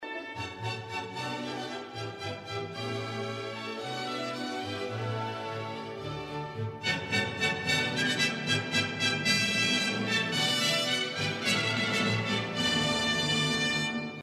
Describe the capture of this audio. I cannot tell the difference in between the .wav and 128 kbps mp3 file.